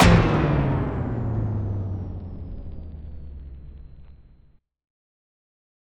Synth Fx Stab 03.wav